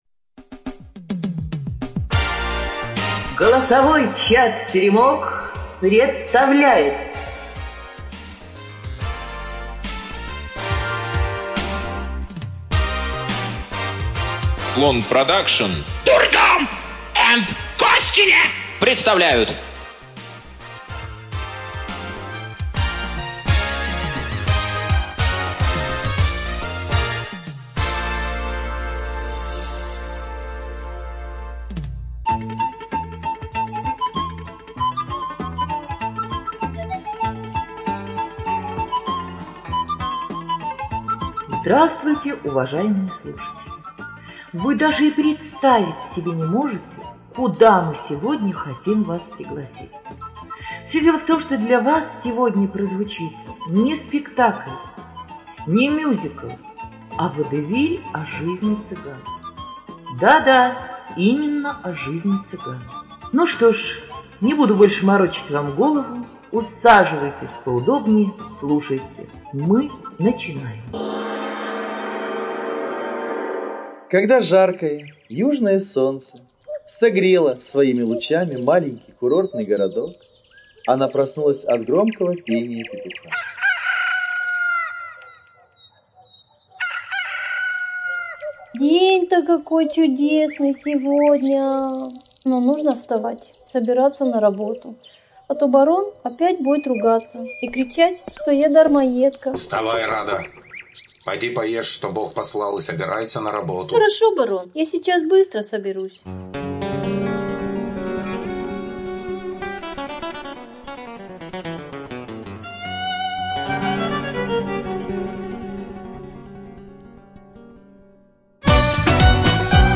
Студия звукозаписиТеремок 4